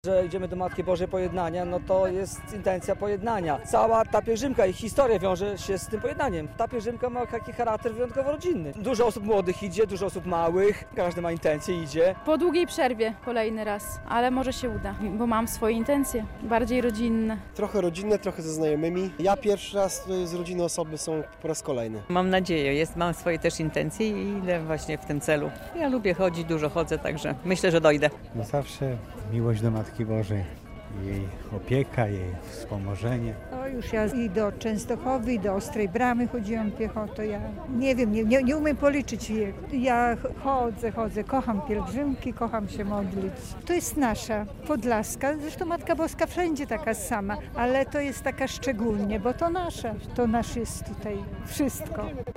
Pielgrzymka do Hodyszewa - relacja